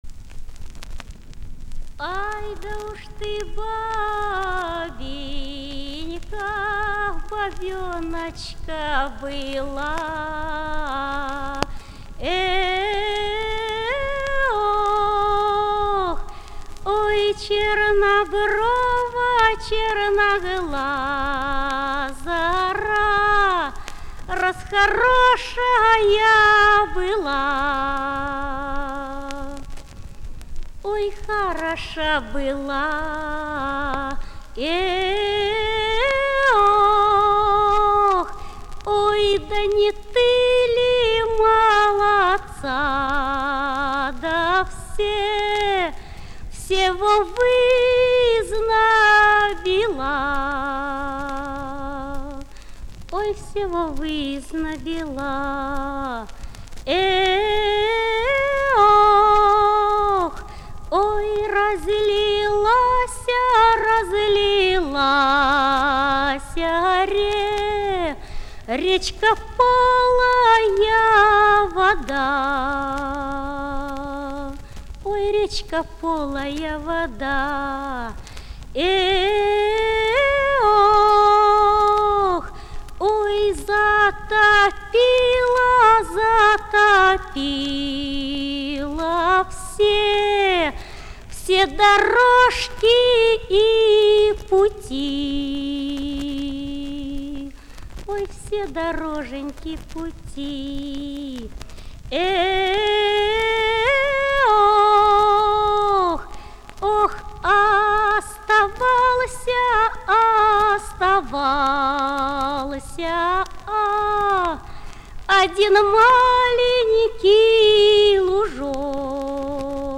воронежская певица Мария Мордасова.        5   Звуковая стр.5